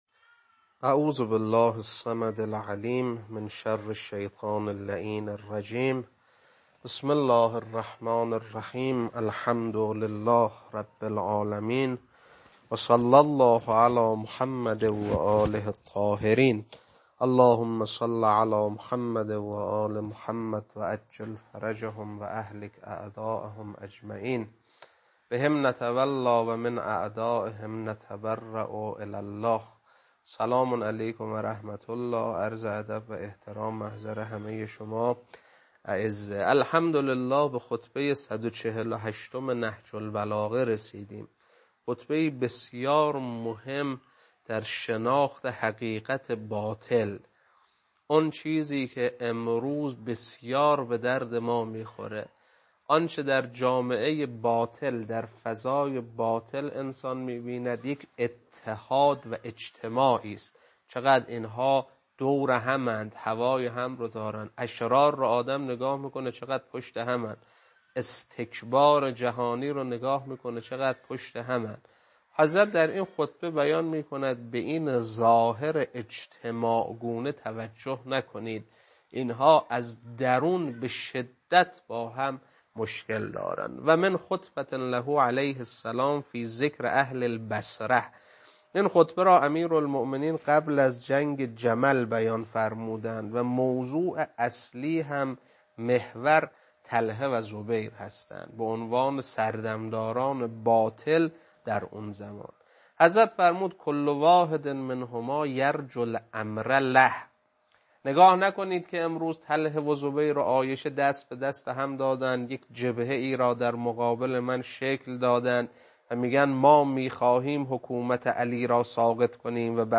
خطبه 148.mp3